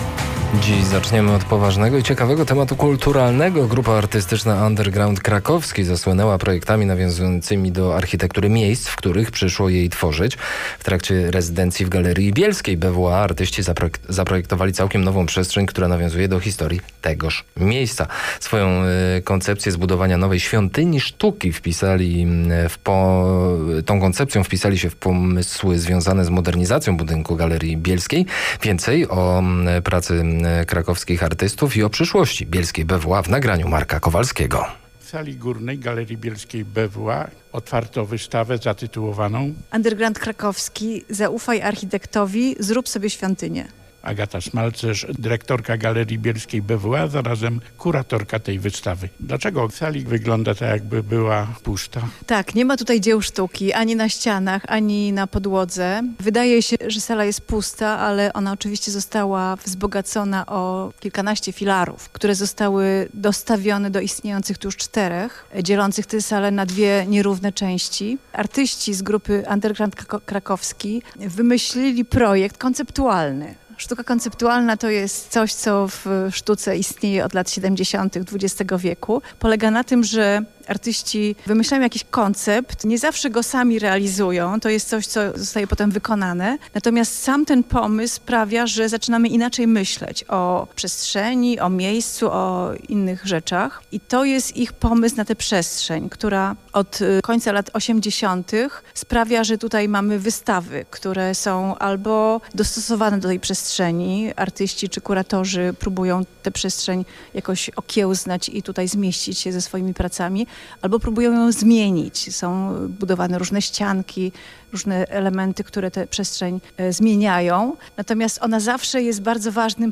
audycja o wystawie ZAUFAJ ARCHITEKTOWI. ZRÓB SOBIE ŚWIĄTYNIĘ, Polskie Radio Katowice